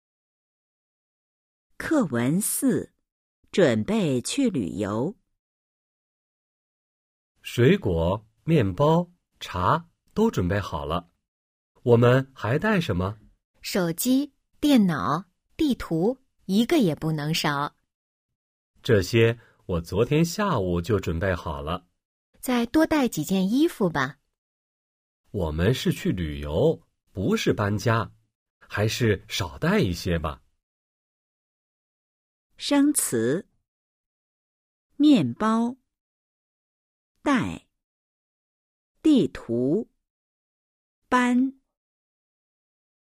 Bài hội thoại 4: 🔊 准备去旅游 – Chuẩn bị đi du lịch  💿 01-04